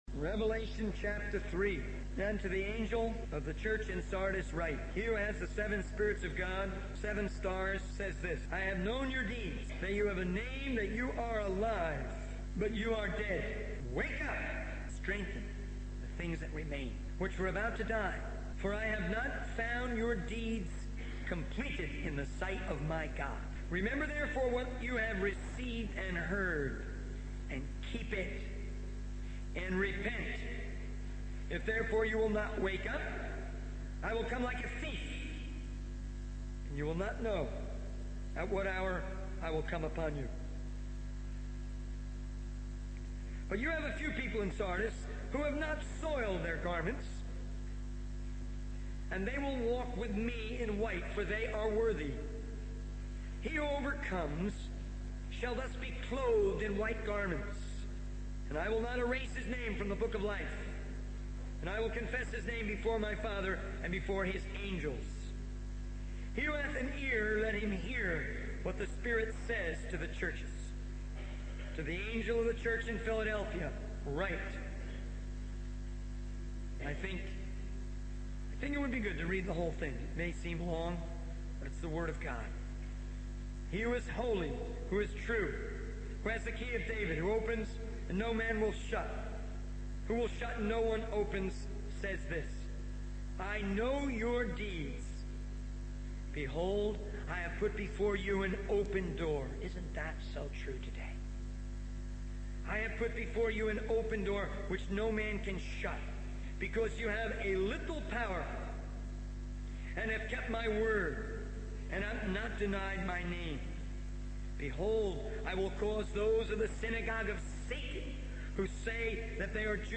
In this sermon, the speaker emphasizes the importance of being committed to sharing the message of Jesus Christ with the unsaved. He expresses concern that many believers are out of touch with reality and not actively reaching out to their neighbors and strangers. The speaker also discusses the need for discipline in various areas of life, such as body, mind, tongue, sleep, food, and thinking.